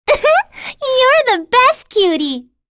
Annette-Vox_Victory_b.wav